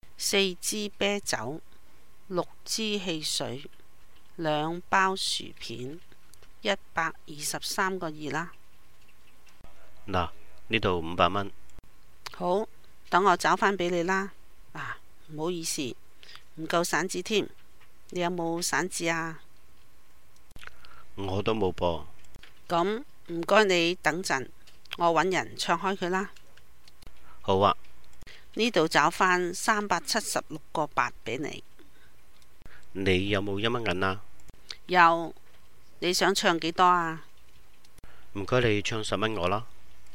BC L9 V1: Customer and clerk in a grocery store